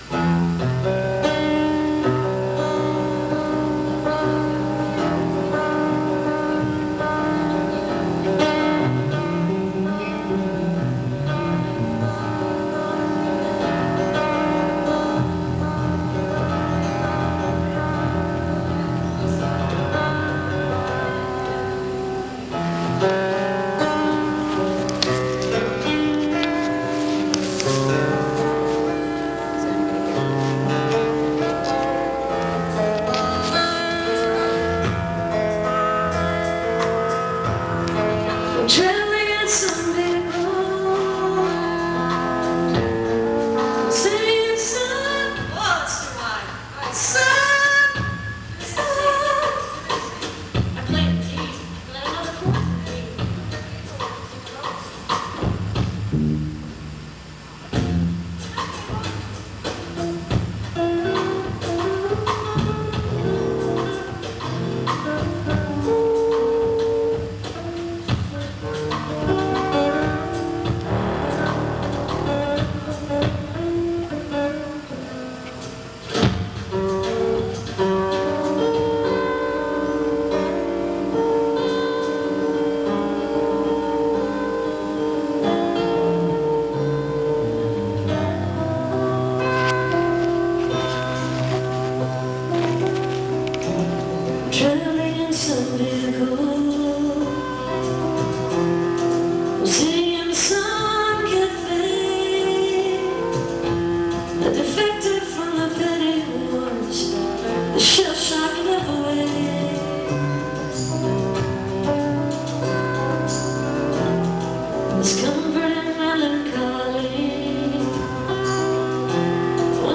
(band show)
(soundcheck)